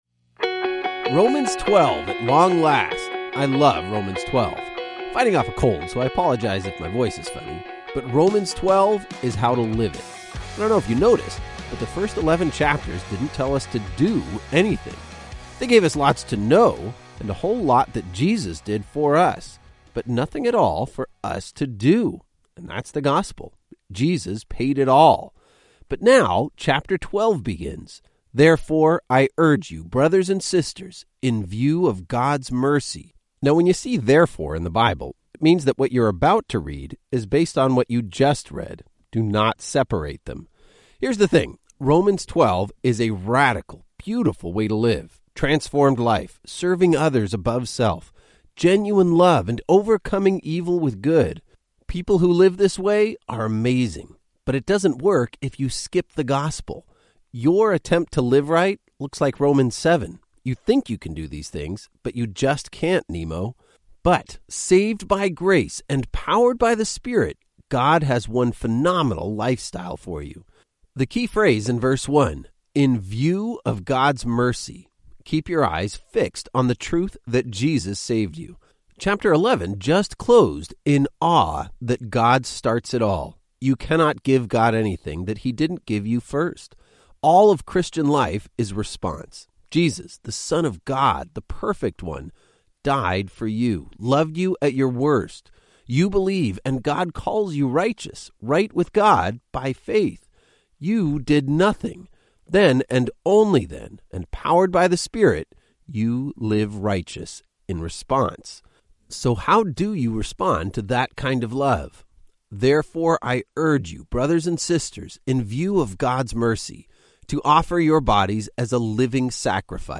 19 Journeys is a daily audio guide to the entire Bible, one chapter at a time. Each journey takes you on an epic adventure through several Bible books, as your favorite pastors clearly explain each chapter in under ten minutes. Journey #3 is Foundations, where Genesis takes us back to our origins, Daniel delivers phenomenal prophecies, and Romans lays out the heart of the gospel.